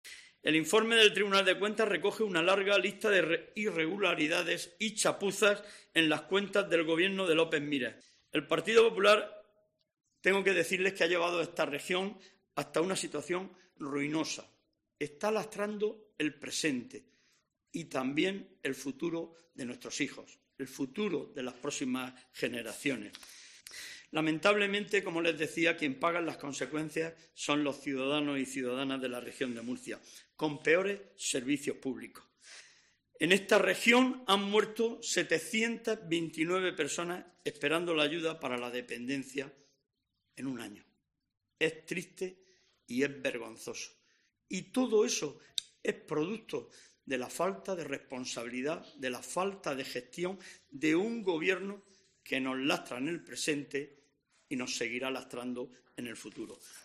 José Vélez, secretario general del PSOE de la Región de Murcia
Vélez ha hecho estas declaraciones en una rueda de prensa en la que ha recordado que el Informe de Fiscalización de la Cuenta General y del resto de cuentas anuales del sector público de la Región de Murcia de 2020 del Tribunal de Cuentas se hizo público en junio y fue enviado a la Asamblea Regional el pasado mes de septiembre.